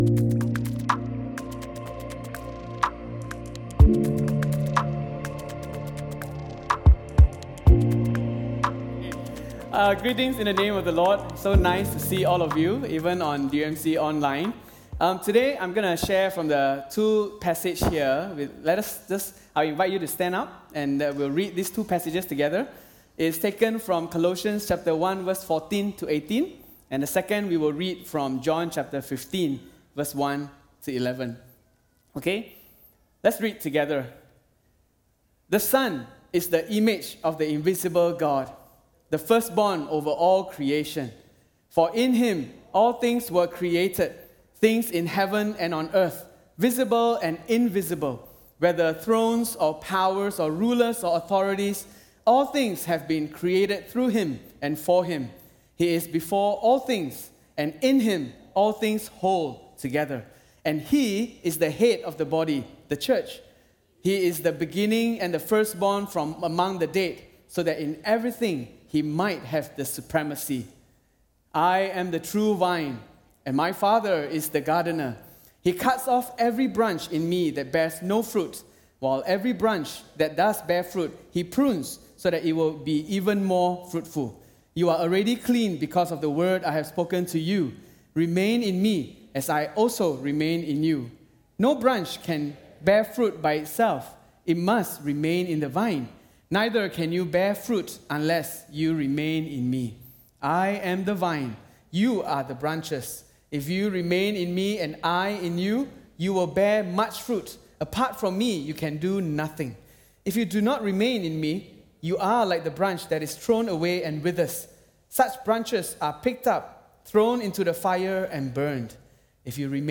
All Sermons What does the church do for me?